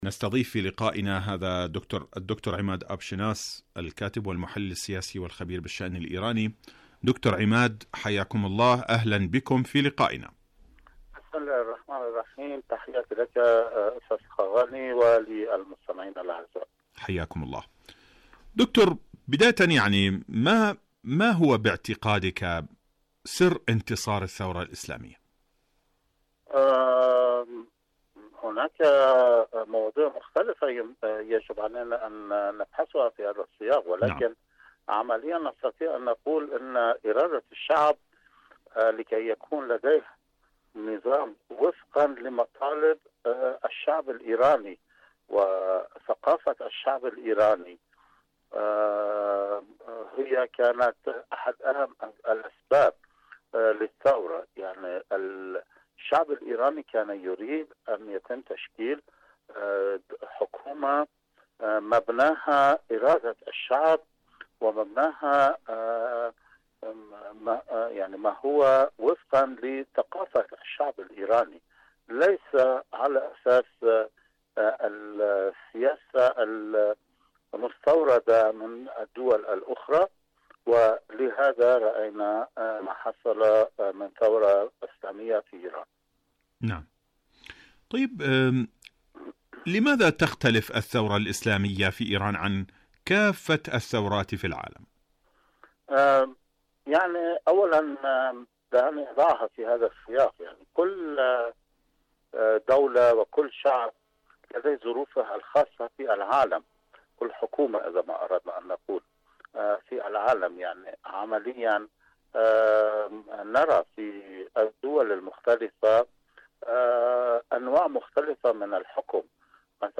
إذاعة طهران العربية برنامج حدث وحوار مقابلات إذاعية